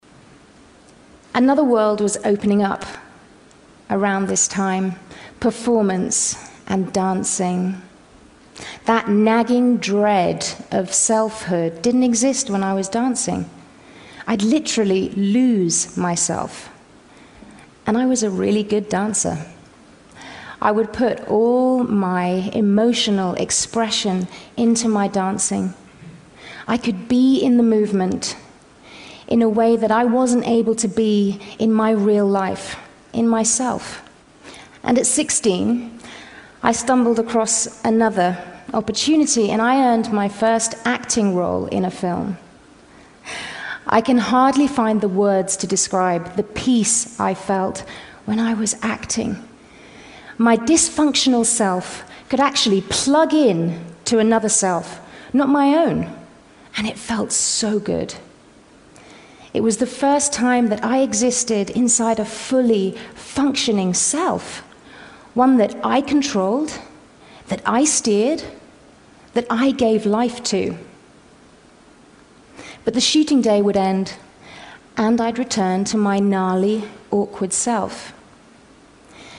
TED演讲:为什么要跟别人一样?(3) 听力文件下载—在线英语听力室